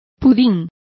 Complete with pronunciation of the translation of puddings.